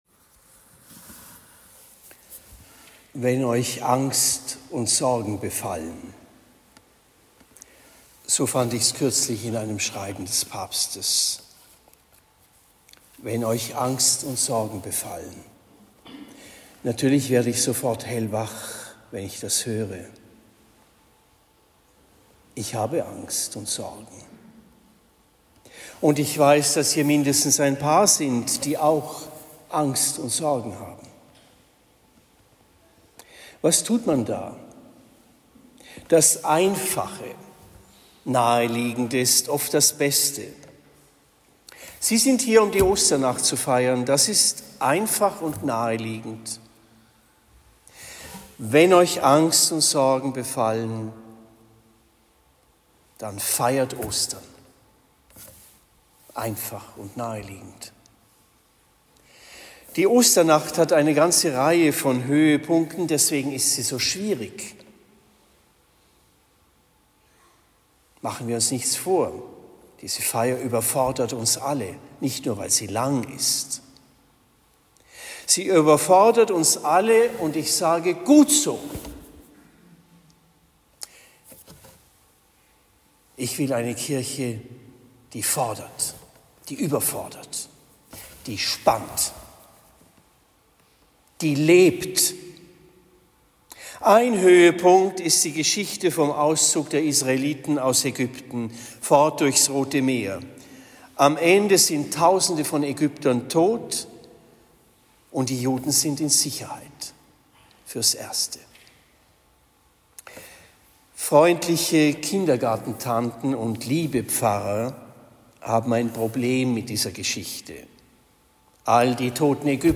Osternacht 2025 Predigt am 19. April 2025 in Trennfeld